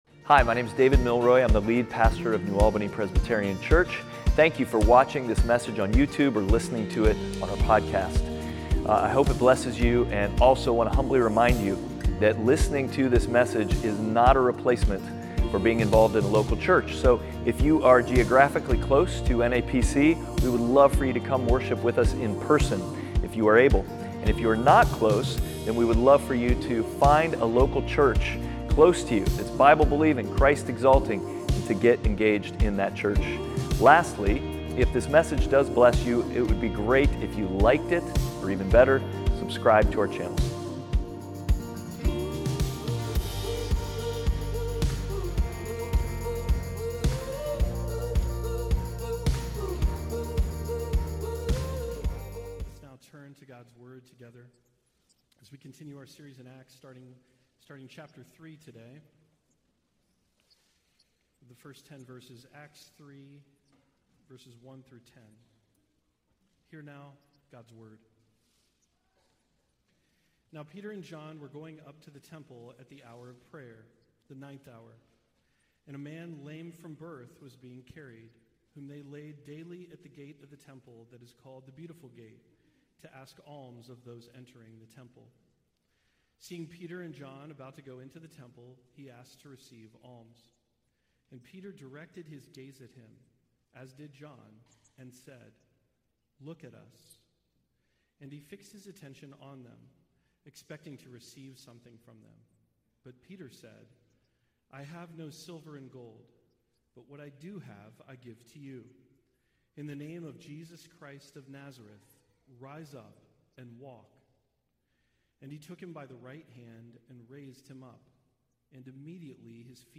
Outward Passage: Acts 3:1-10 Service Type: Sunday Worship « Outward